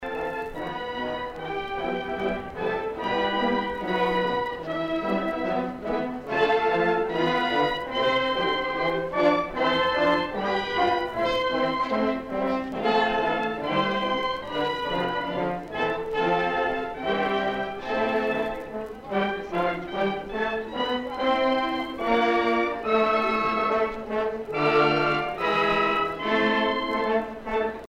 montage de reportage de la Ducace de 1974